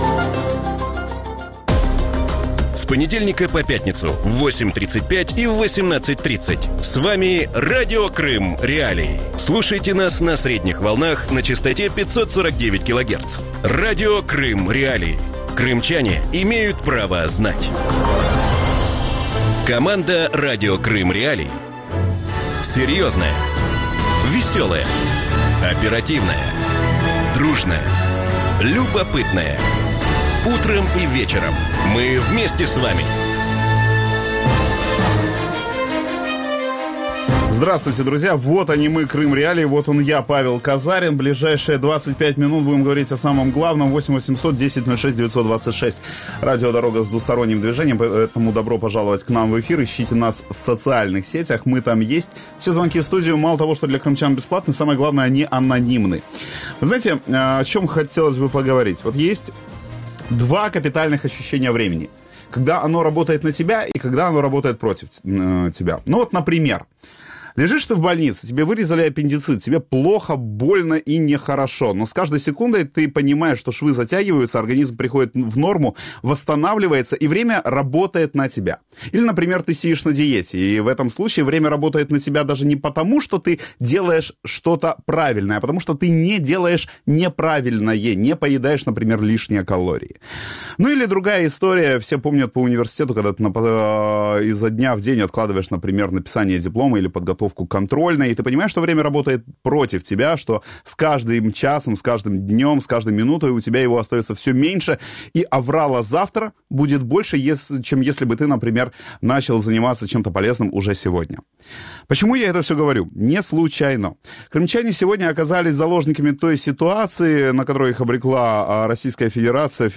У вечірньому ефірі Радіо Крим.Реалії обговорюють можливості дистанційної освіти для кримчан, які залишаються на півострові. Чи можна отримати визнаний диплом про вищу освіту, навчаючись на дому, а також які є можливості для підвищення кваліфікації та вивчення нових предметів для кримчан?